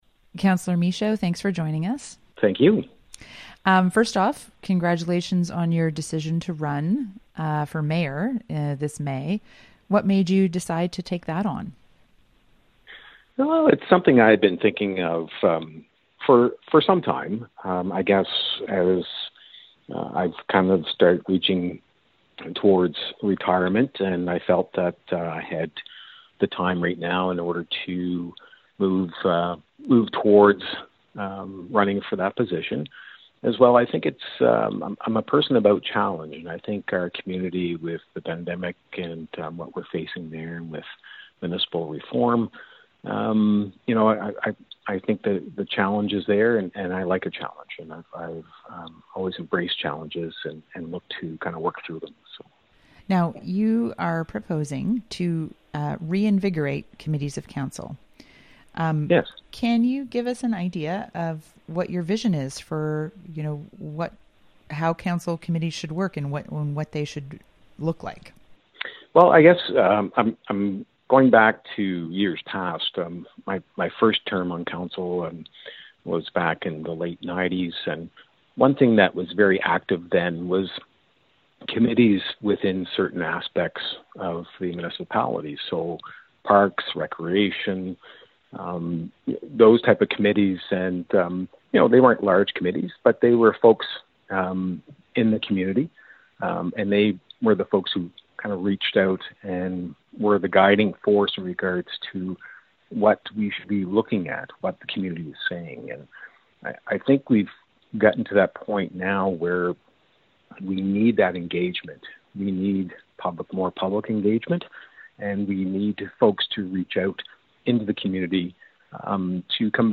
Hear Shawn Mesheau talking about his committee renewal proposal on Tantramar Report, here: